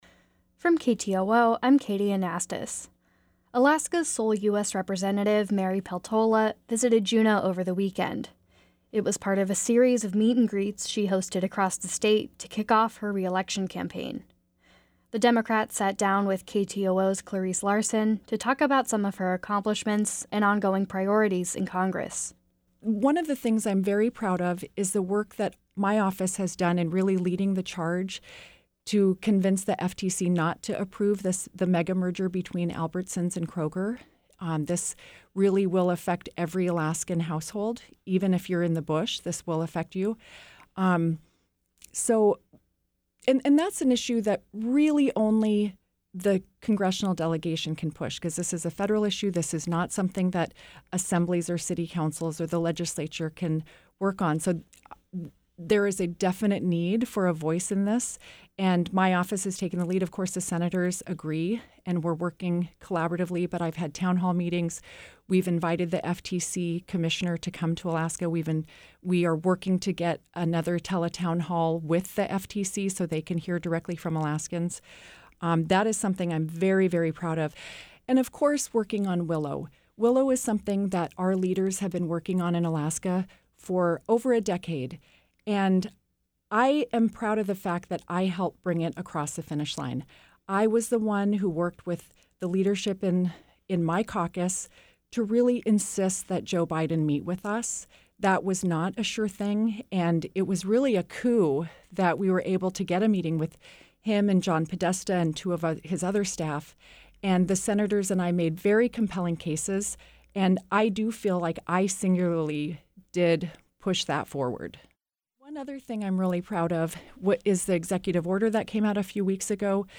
Newscast – Tuesday, Jan. 30, 2024